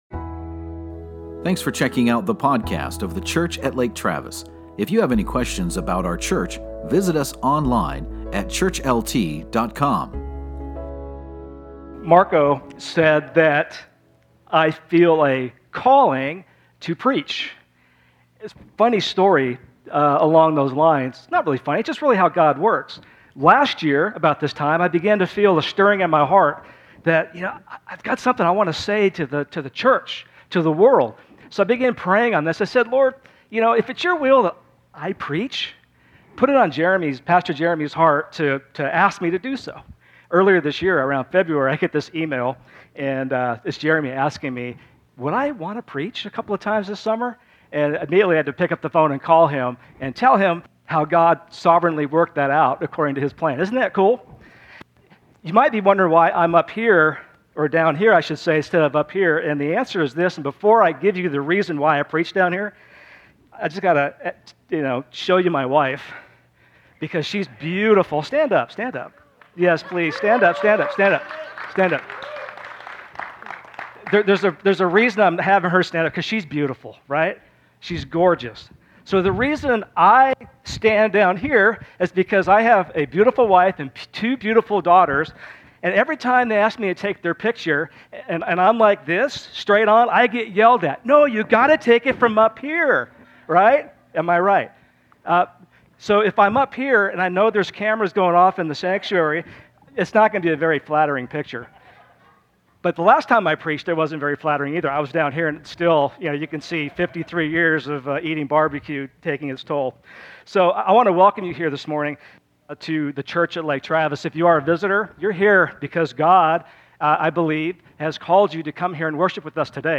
Take a listen to this Sunday’s message